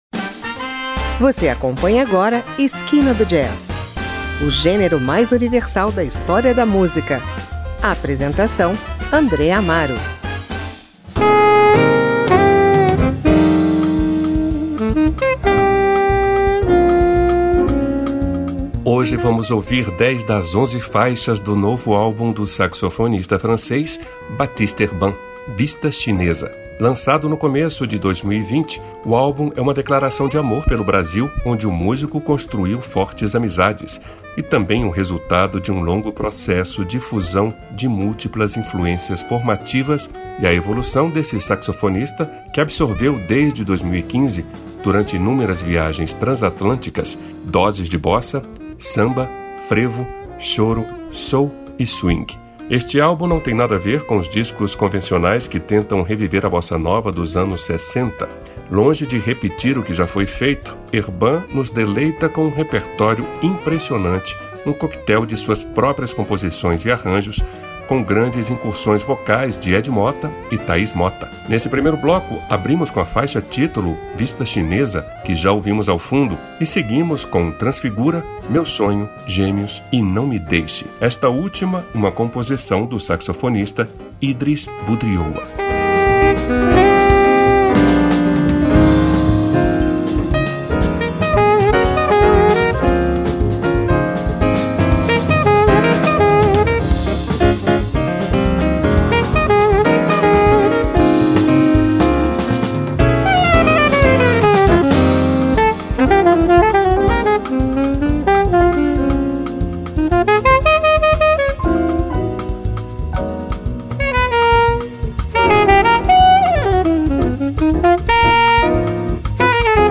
saxofonista francês